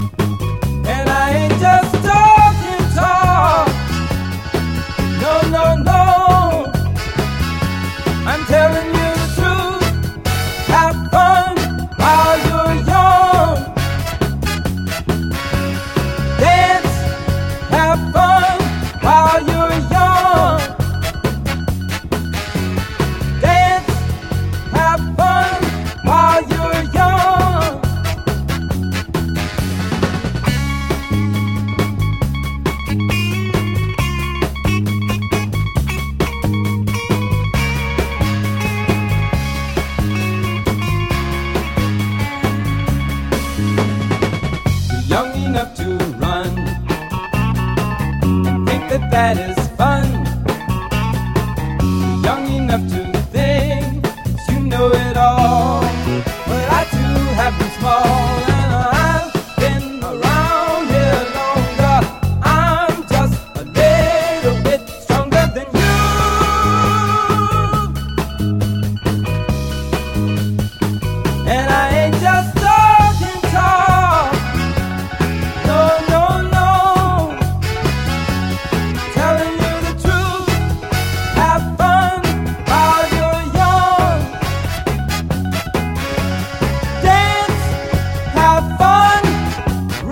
Legitimate repress of this long lost Soul / Funk classic.
Funk / soul